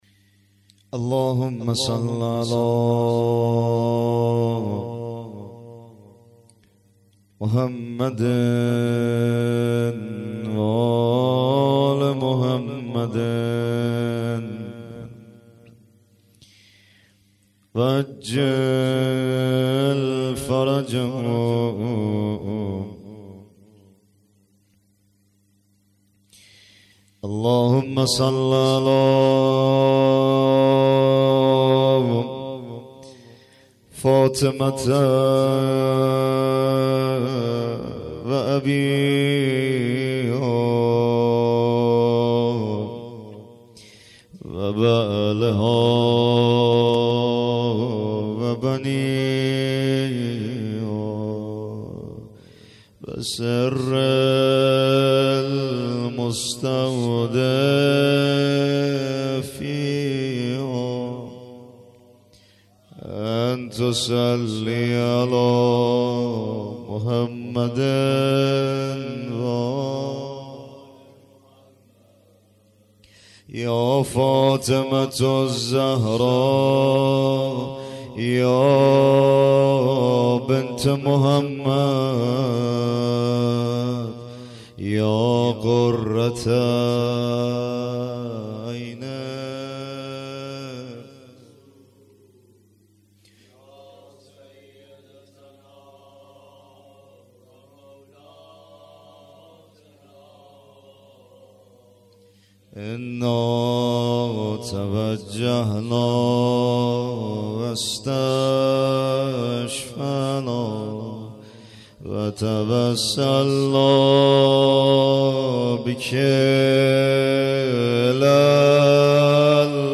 مناجات با امام زمان (عج) | روضه علی اکبر ( ع )